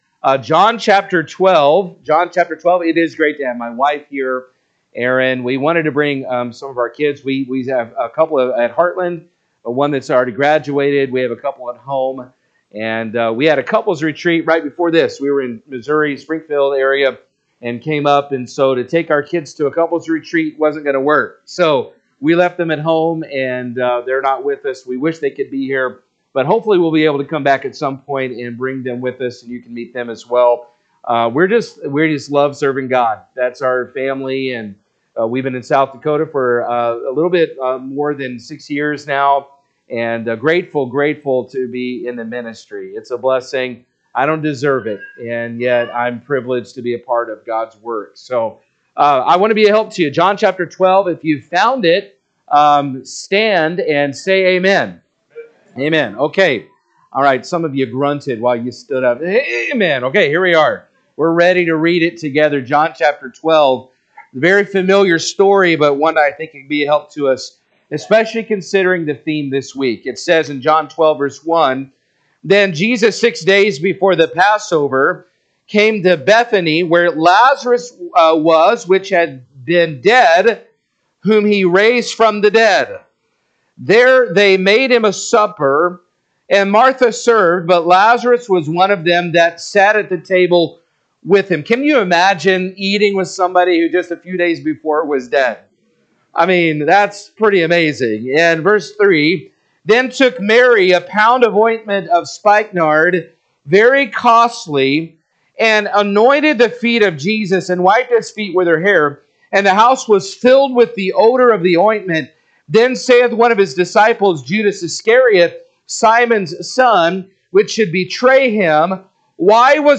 September 28, 2025 AM Mission Revival Service